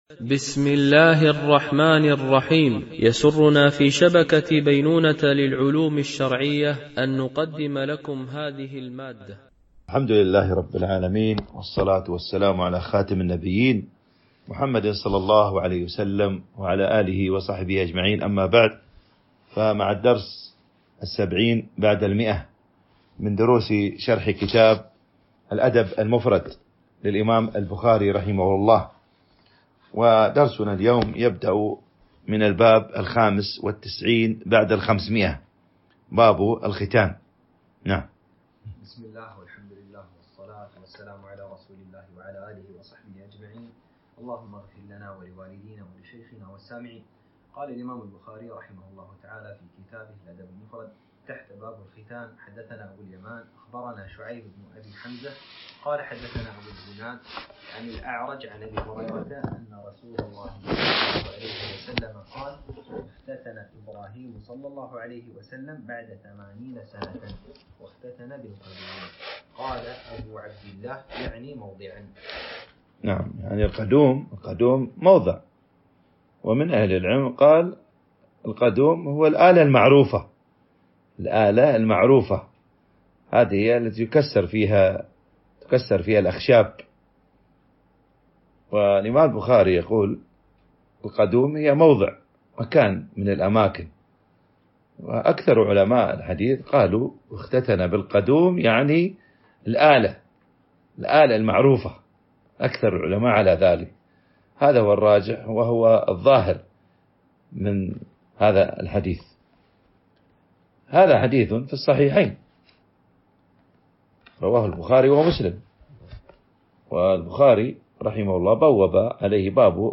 التنسيق: MP3 Mono 44kHz 64Kbps (VBR)